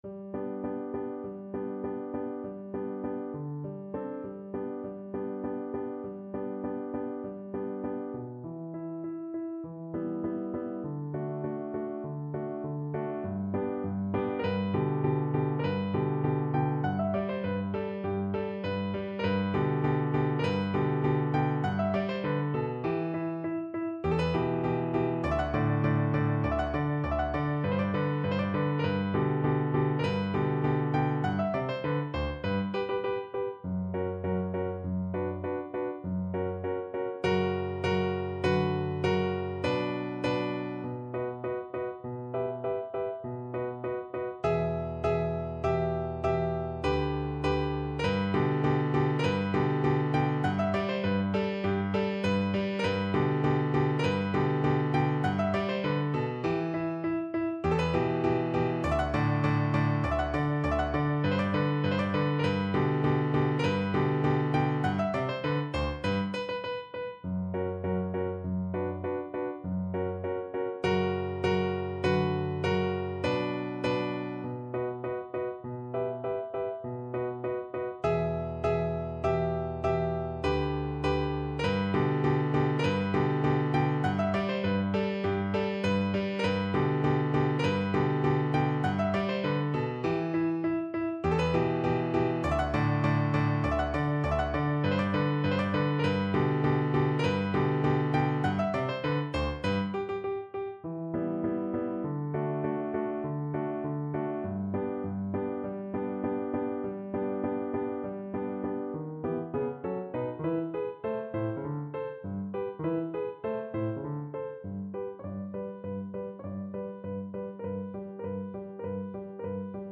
Beethoven: Marsz turecki (na skrzypce i fortepian)
Symulacja akompaniamentu